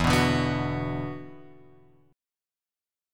E7sus2 chord {0 2 0 x 0 2} chord